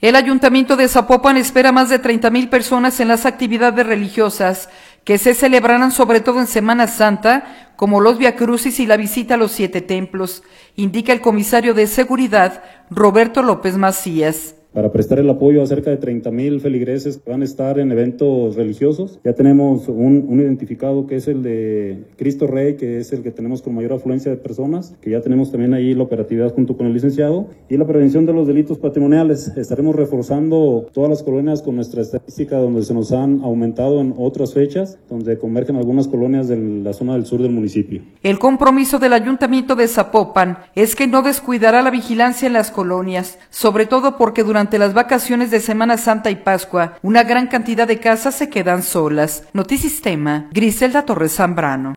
El Ayuntamiento de Zapopan espera más de 30 mil personas en las actividades religiosas que se celebrarán sobre todo en Semana Santa como los viacrucis y la visita de los siete templos, indica el Comisario de Seguridad, Roberto López Macías.